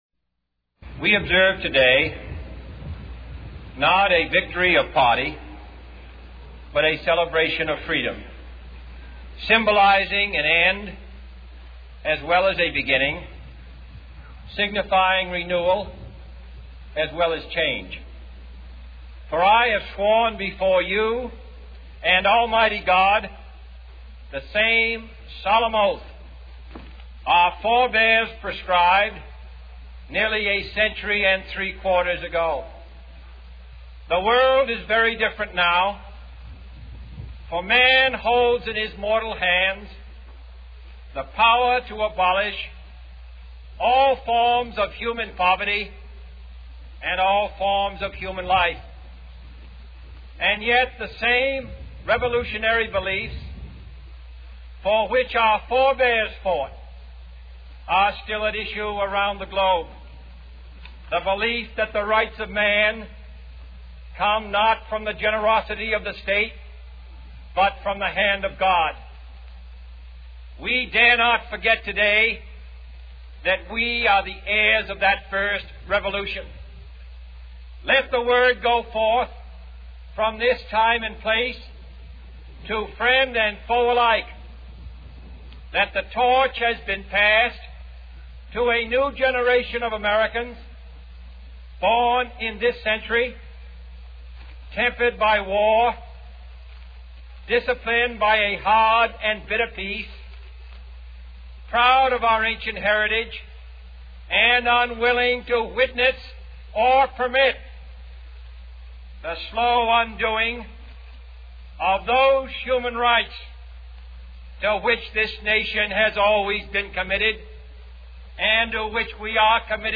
John F. Kennedy: Inaugural Address
[Administering of the Oath of Office]